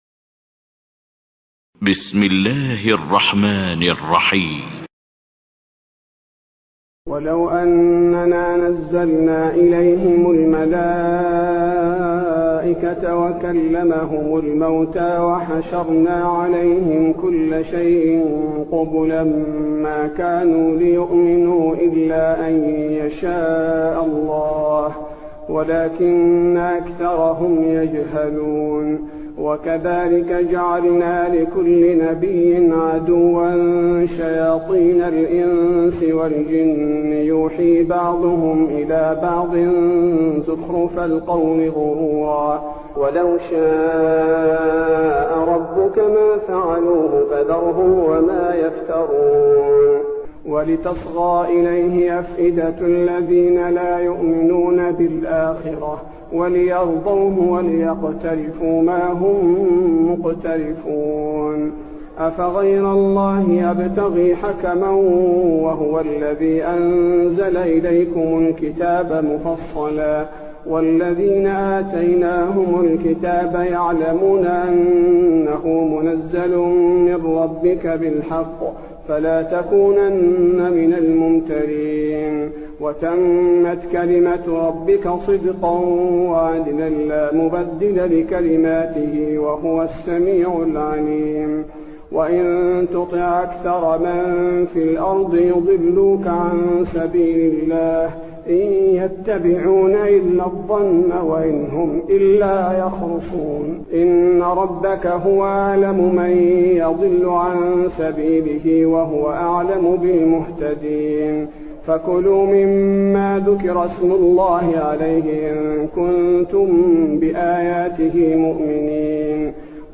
تهجد ليلة 26 رمضان 1423هـ من سورة الأنعام (111-165) Tahajjud 26 st night Ramadan 1423H from Surah Al-An’aam > تراويح الحرم النبوي عام 1423 🕌 > التراويح - تلاوات الحرمين